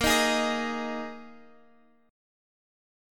Bb7sus4 Chord